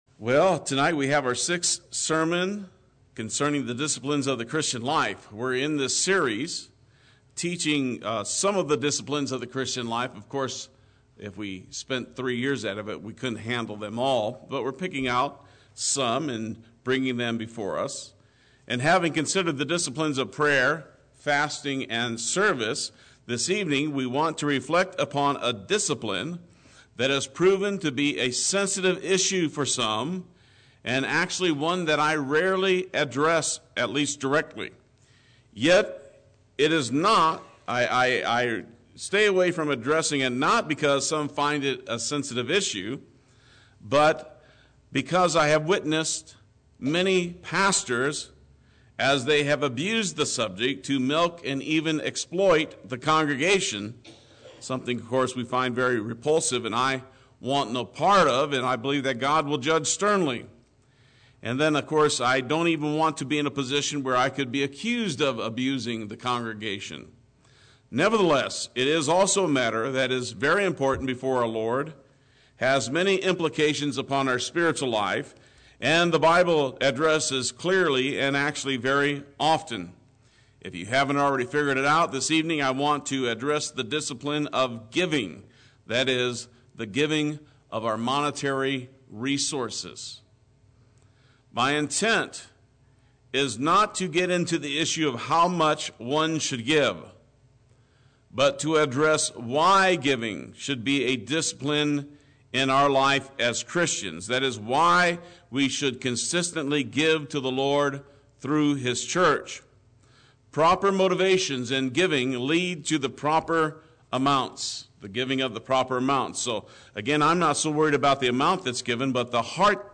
Play Sermon Get HCF Teaching Automatically.
Giving Wednesday Worship